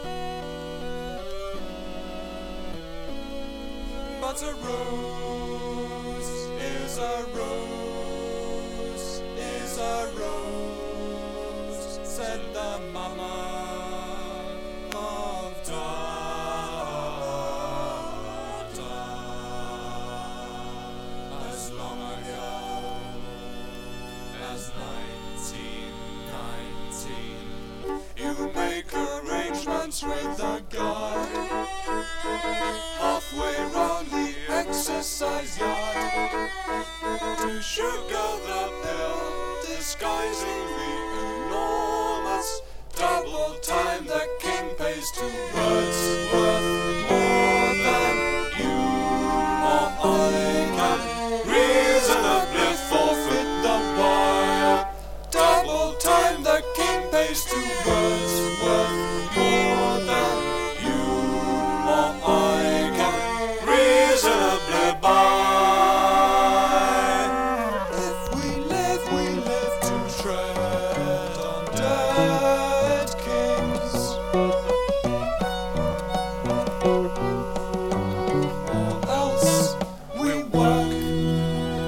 知的で官能的だけど聴きやすい？不思議なサウンド。